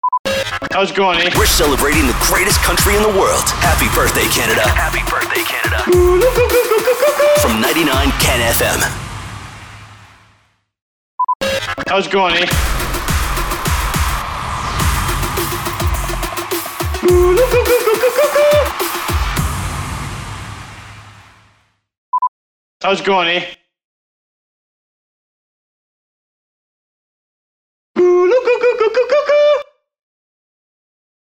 012 – SWEEPER – HAPPY BIRTHDAY CANADA
012-SWEEPER-HAPPY-BIRTHDAY-CANADA.mp3